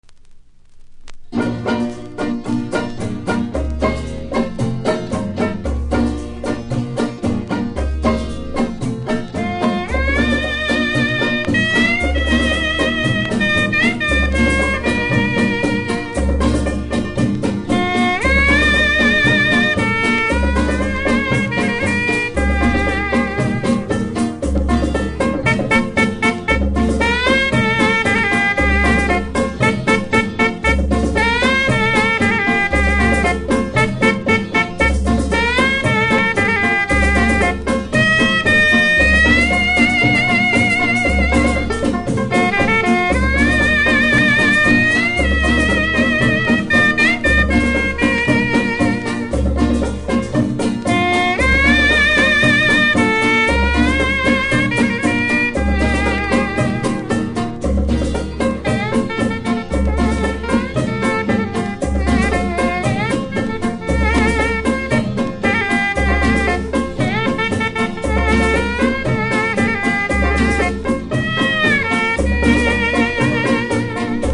キズはそこそこありますがノイズは少なく良好盤です。